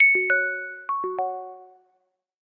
알림음 8_딩동.ogg